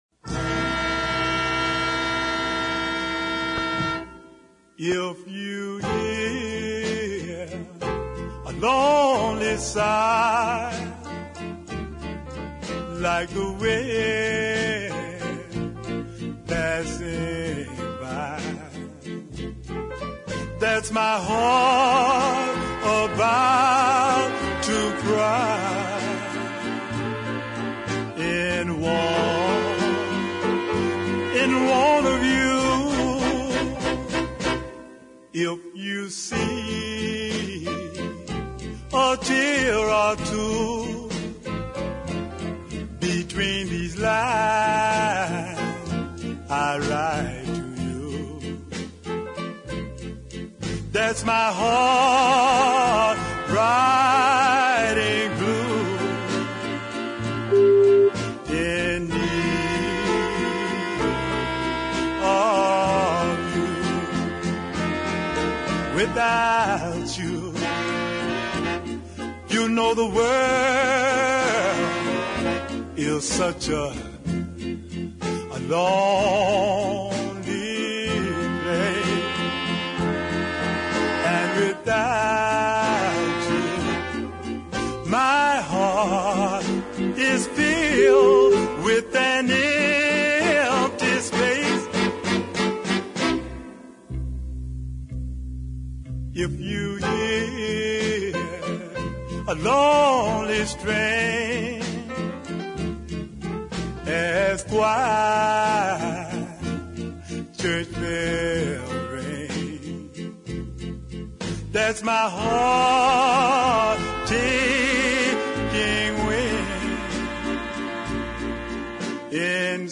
vinyl condition: G (some surface noise)